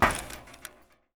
metal_plate4.ogg